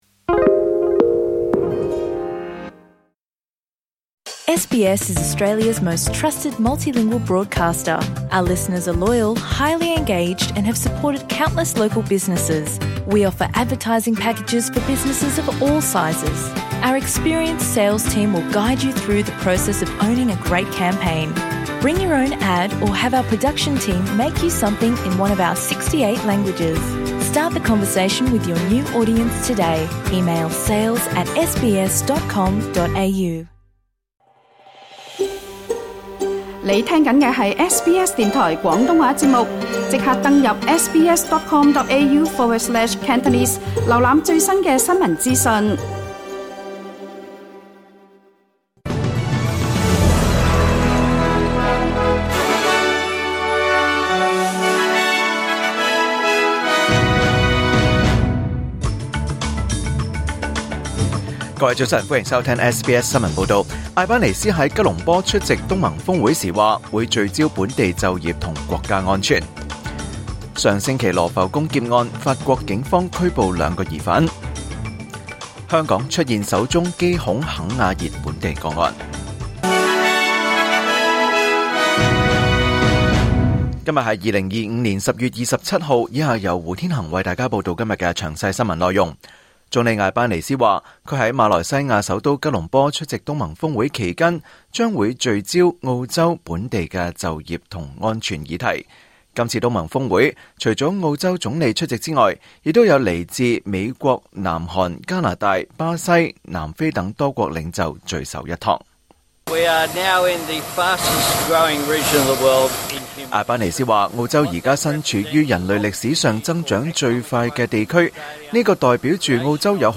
2025年10月27日SBS廣東話節目九點半新聞報道。